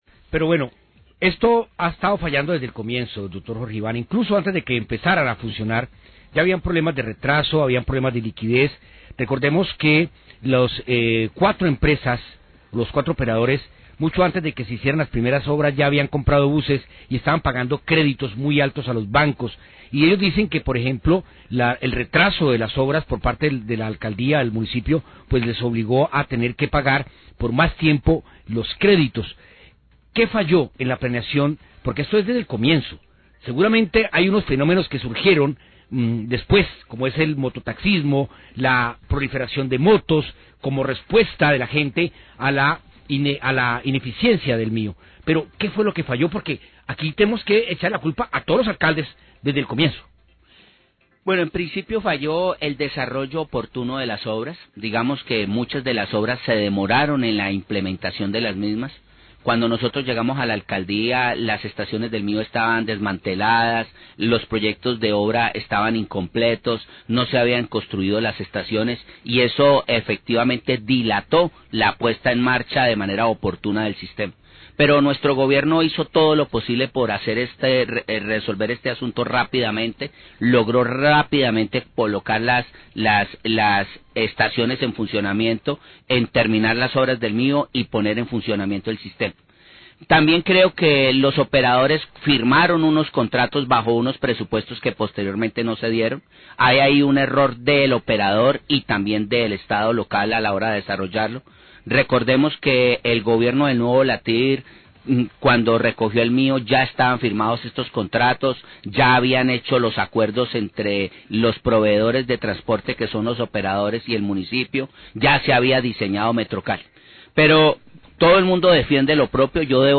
Ex alcalde Jorge Iván Ospina, habla acerca del desarrollo del transporte masivo MIO.